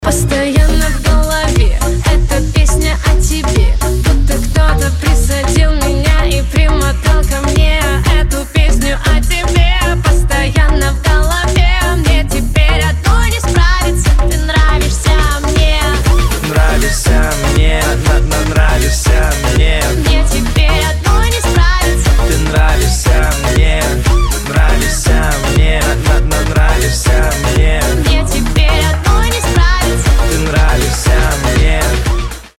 • Качество: 320, Stereo
поп
забавные